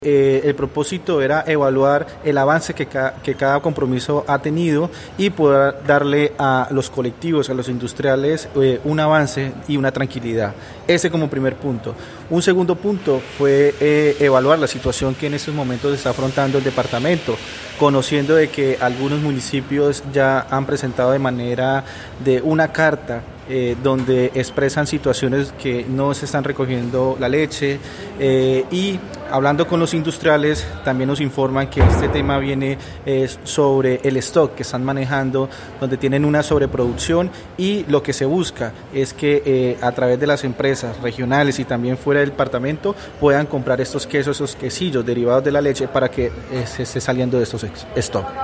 Así lo dio a conocer el secretario de agricultura, Juan Pablo Jaramillo.